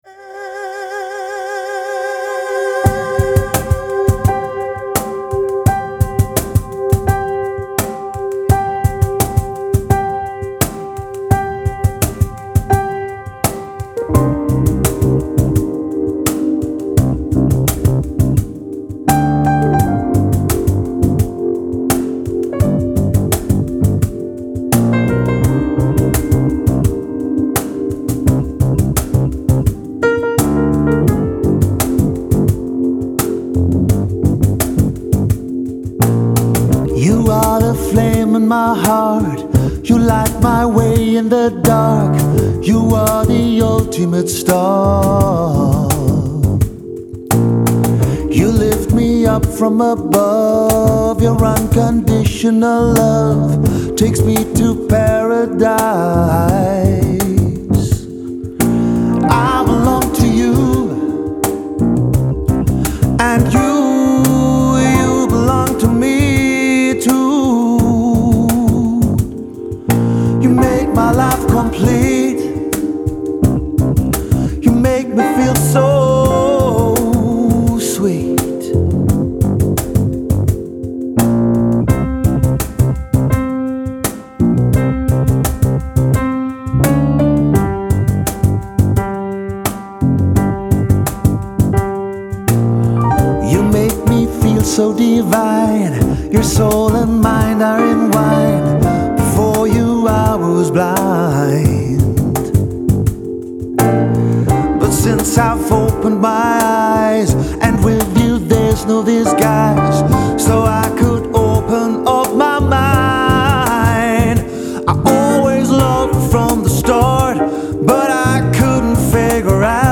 The Piano Rhodes Vocal Experience